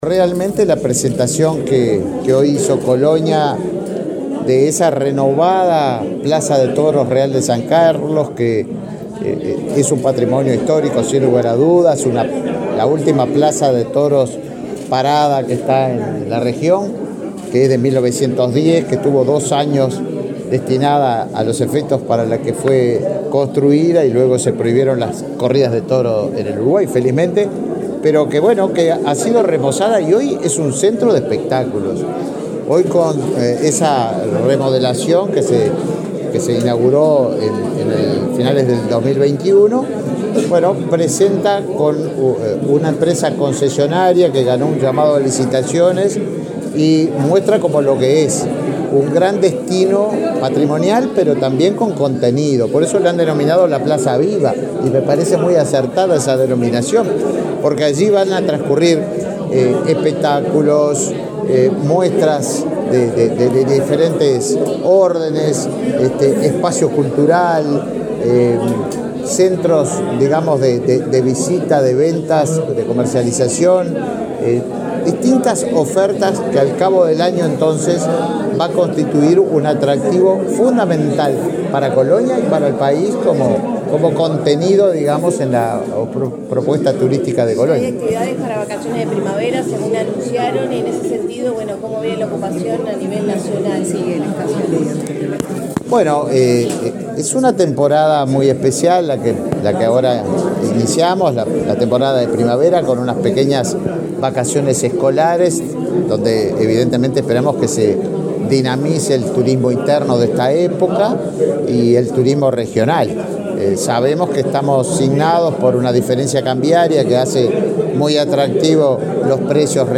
Declaraciones del ministro de Turismo, Tabaré Viera
Luego dialogó con la prensa.